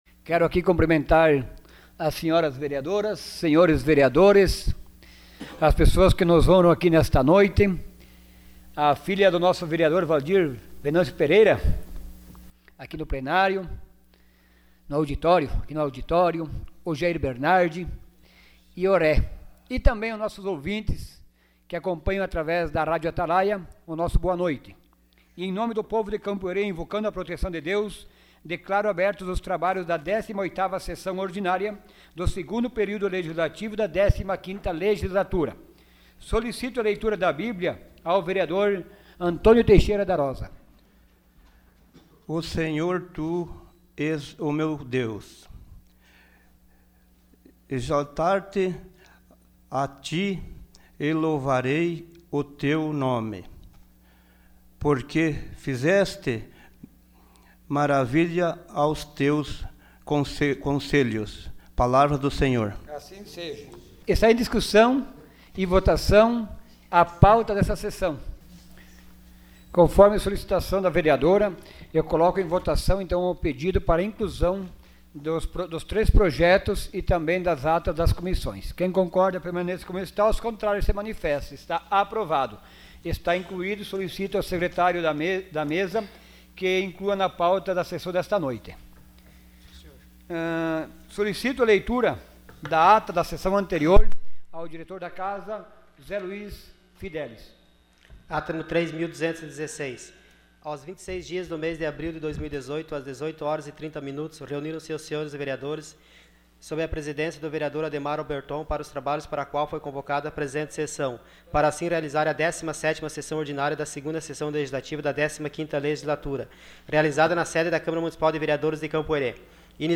Sessão Ordinária dia 30 de abril de 2018.